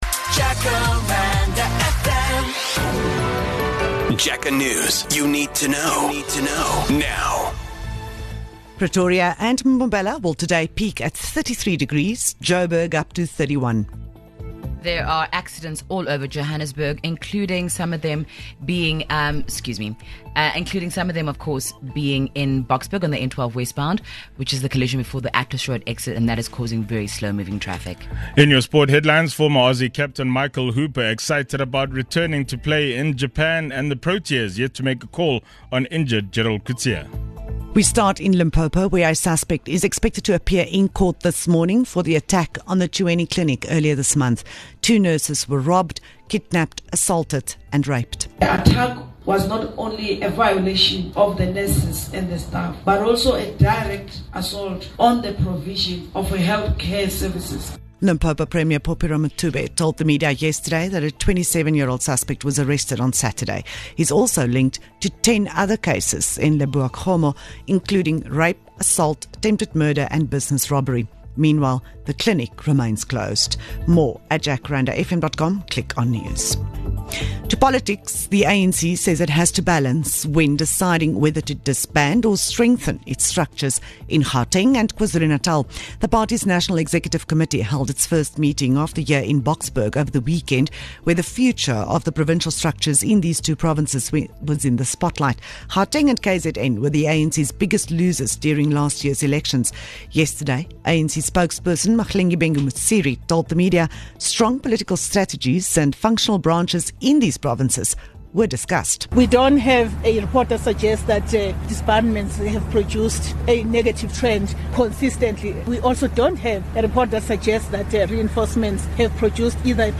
South Africa Current Affairs South Africa Jacaranda FM International News News Daily News